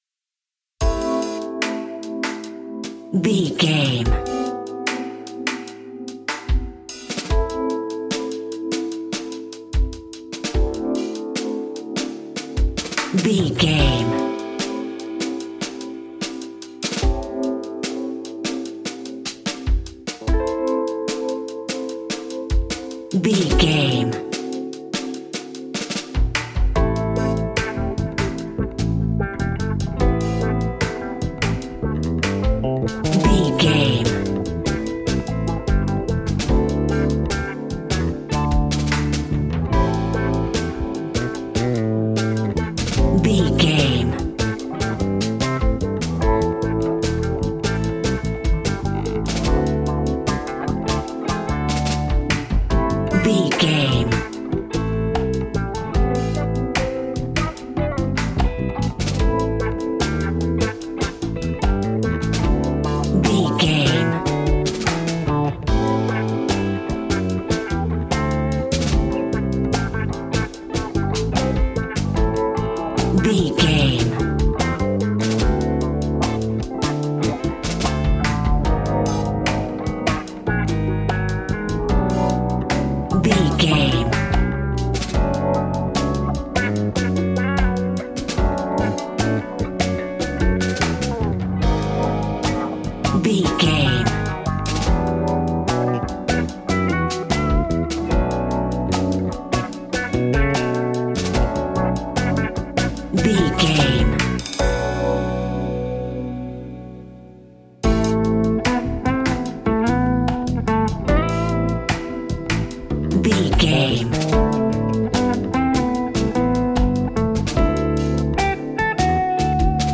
Fast paced
Uplifting
Ionian/Major
hip hop